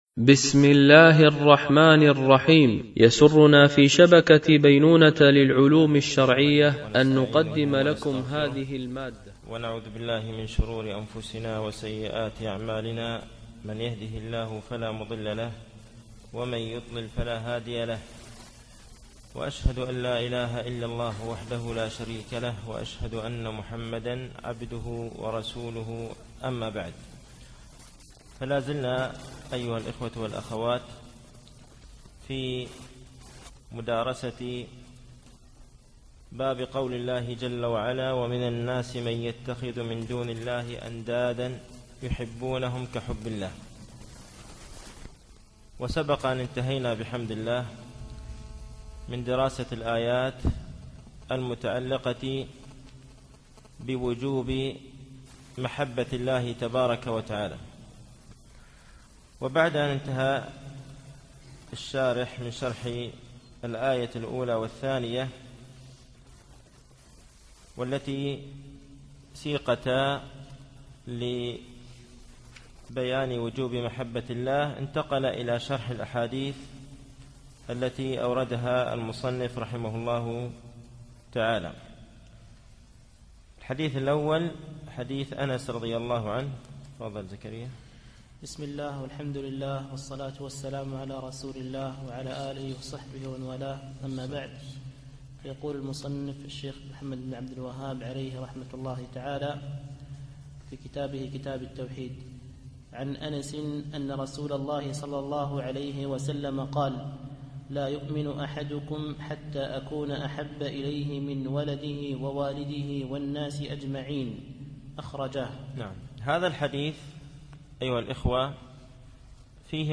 التعليق على القول المفيد على كتاب التوحيد ـ الدرس الأول بعد المئة